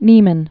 (nēmən, nyĕ-)